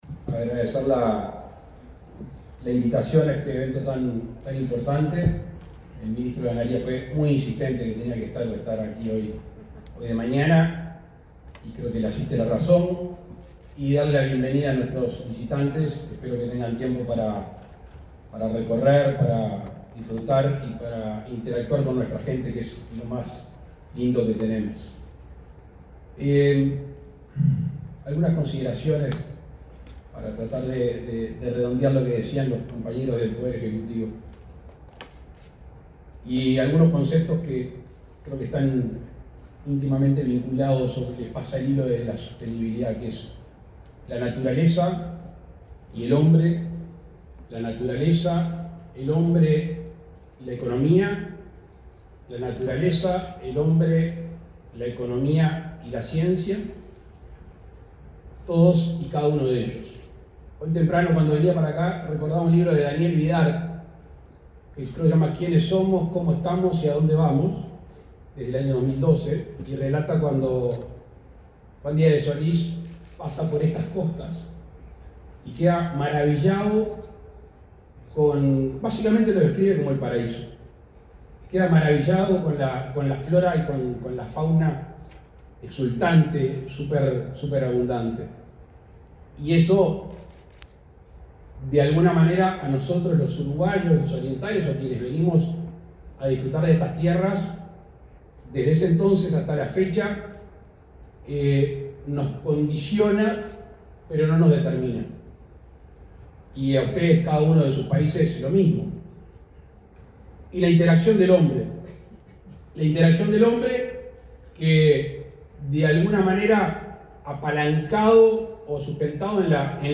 Palabras del presidente Luis Lacalle Pou
La Organización de las Naciones Unidas para la Alimentación y la Agricultura (FAO), el Ministerio de Ganadería, Agricultura y Pesca y el Instituto Nacional de Carnes de Uruguay organizaron la primera Conferencia Regional para la Transformación Sostenible de la Ganadería, en Punta del Este, departamento de Maldonado. El presidente de la República, Luis Lacalle Pou, disertó en la apertura.